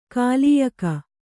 ♪ kālīyaka